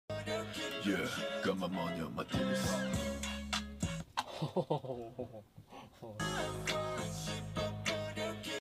Voice Deeper Than The Ocean Sound Effects Free Download